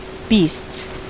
Ääntäminen
Vaihtoehtoiset kirjoitusmuodot (vanhahtava) beastes Ääntäminen US Tuntematon aksentti: IPA : /biːsts/ Haettu sana löytyi näillä lähdekielillä: englanti Käännöksiä ei löytynyt valitulle kohdekielelle.